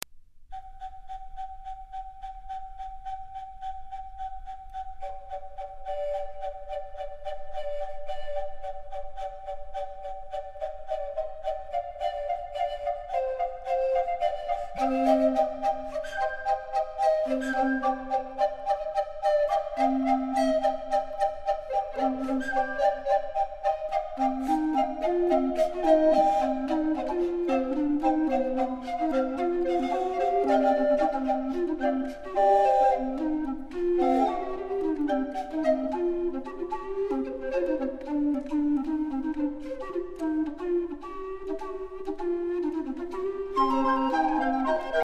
Obsazení: 4 Blockflöten (SATB)